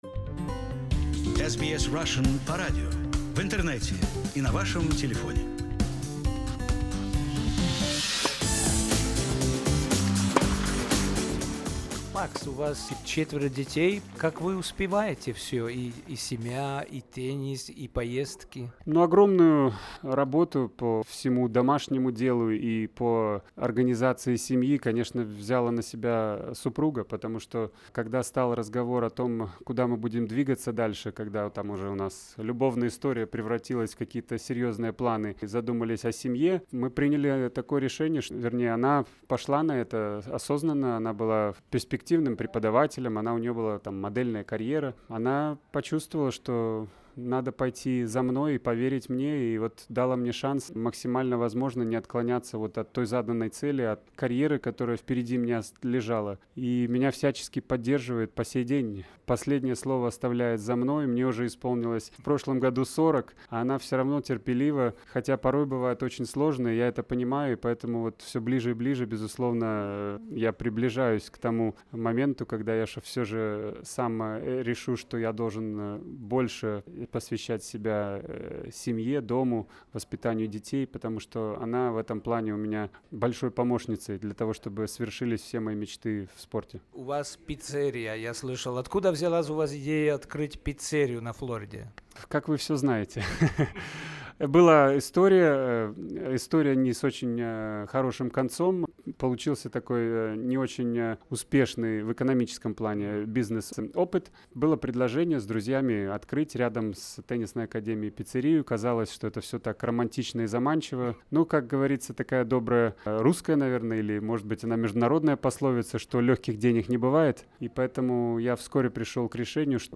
In the third and final part of the extensive interview with veteran of Belorussian tennis Max Mirnyi he speaks of a difficult task to combine intensive professional sports schedule with family life and business undertakings.